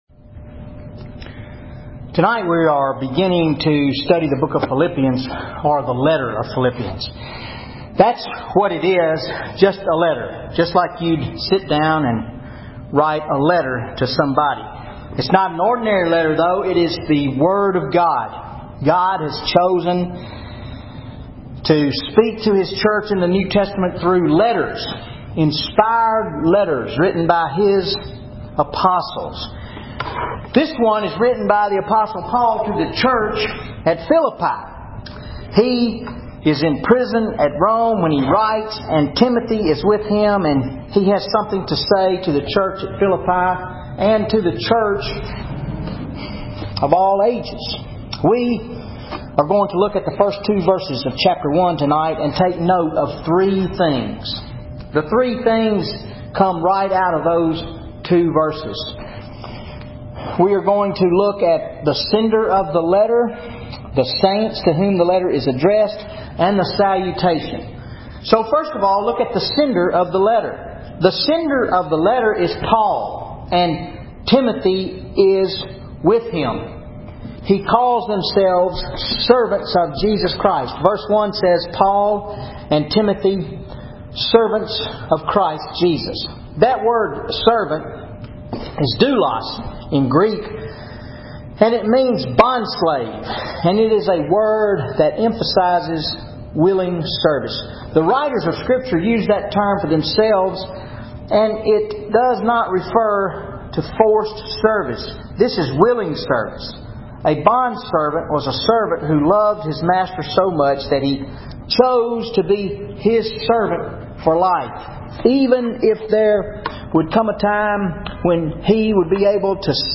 Wednesday Night Bible Study July 24, 2013 Philippians 1:1-2